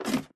ladder1.ogg